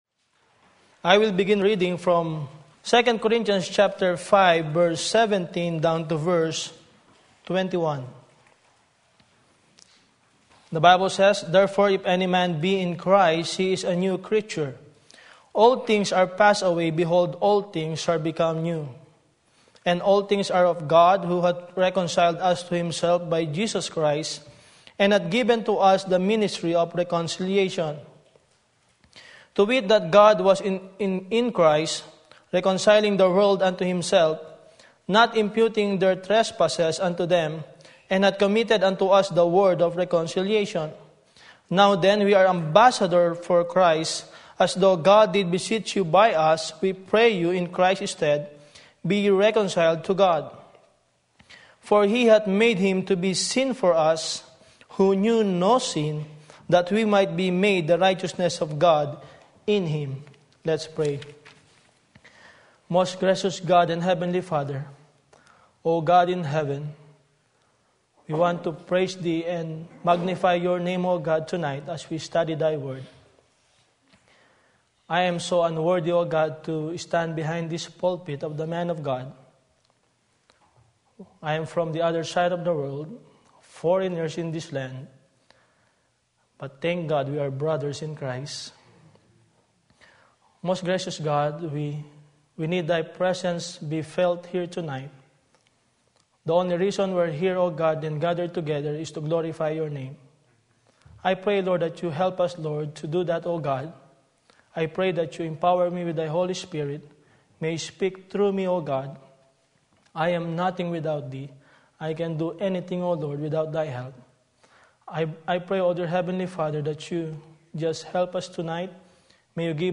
Sermon Link
Matthew 9:35-38 Special Service